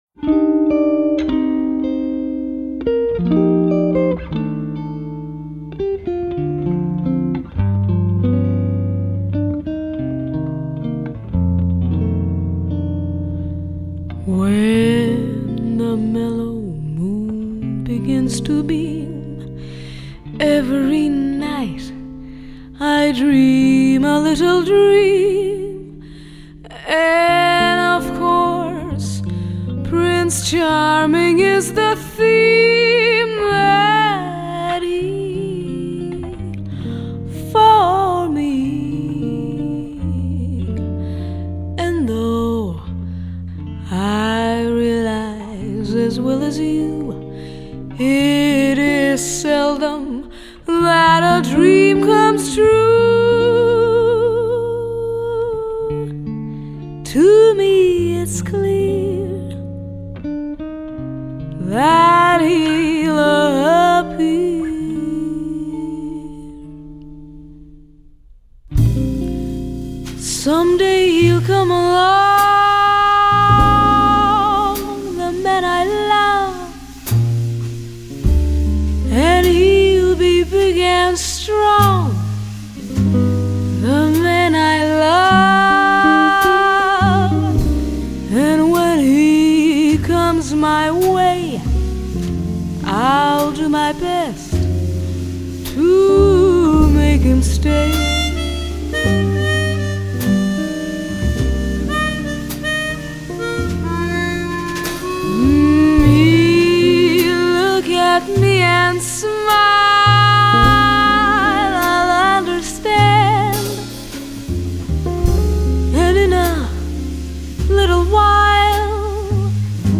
從各國民謠抒情曲 爵士經典曲到當代都會小品
非常夜的感覺 一種精緻華麗的音樂感官享受 一張絕對可以滿足各個樂迷的當代爵士女聲專輯
本張專輯有著絕佳的錄音效果